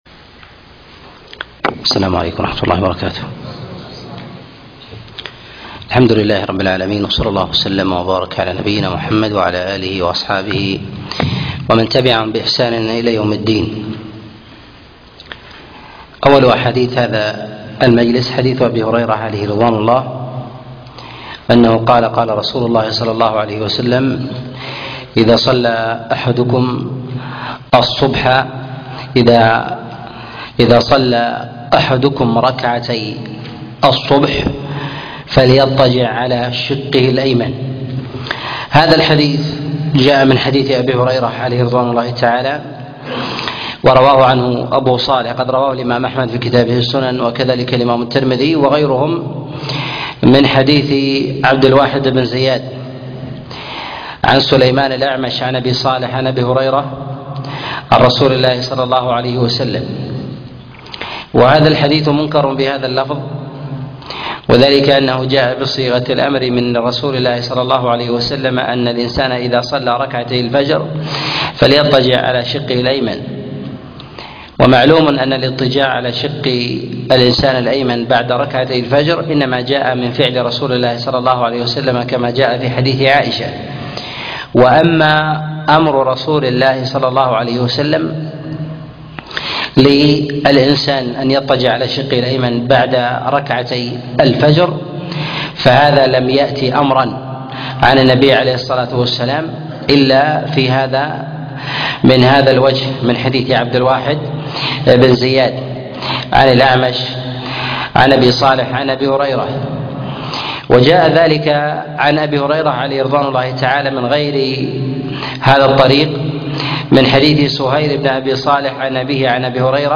الأحاديث المعلة في الصلاة الدرس 12 - الشيخ عبد العزيز بن مرزوق الطريفي